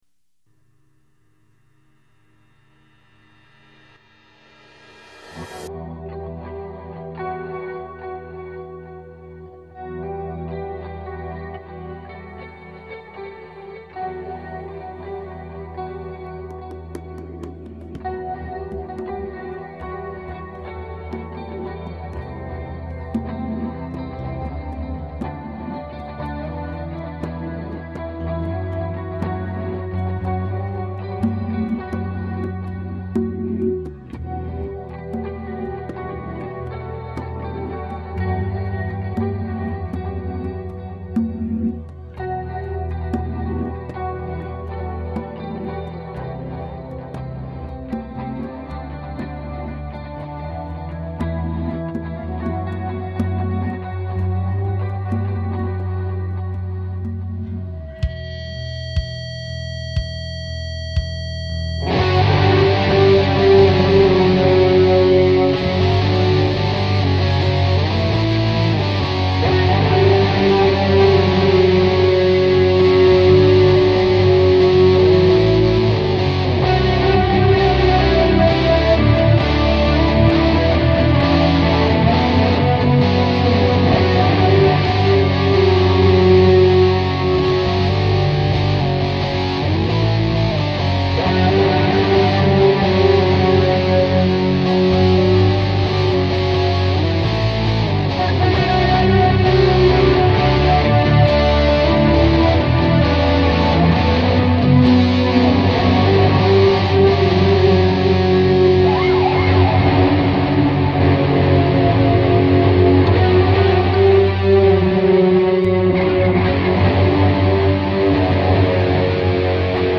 any way its the guitar part and some congas
!i added a bass line as well as a new part!